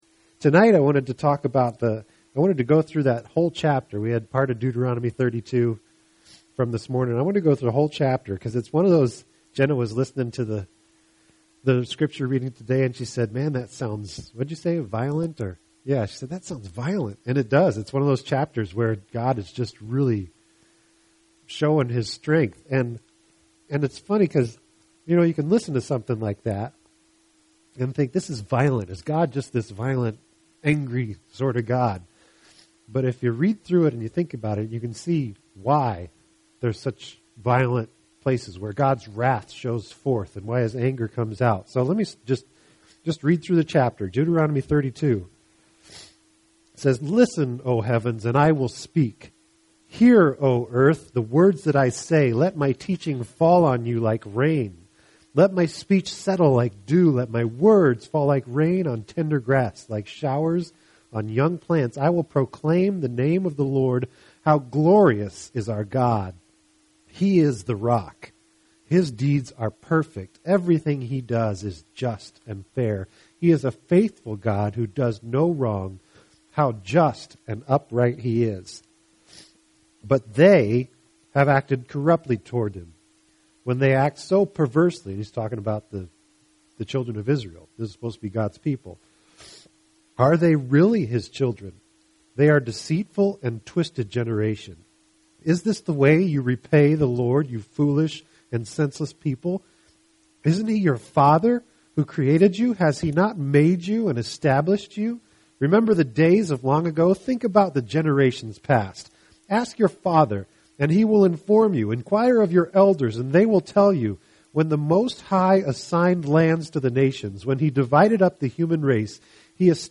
Click on the link to hear the message: Podcast: Play in new window | Download (Duration: 34:07 — 15.6MB) This entry was posted on Sunday, September 2nd, 2012 at 10:40 pm and is filed under Sermons .